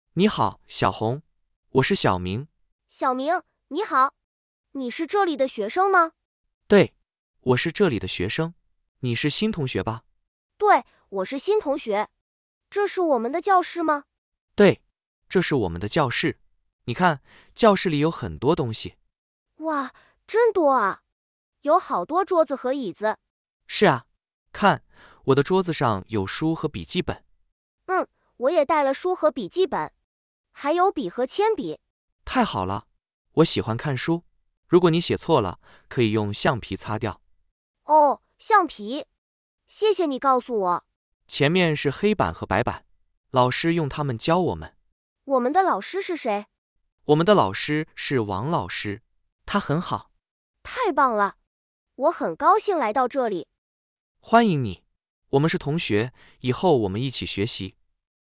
E. Dialog for Practice
D.-Dialog-of-Object-in-the-Classroom-Mandarin.mp3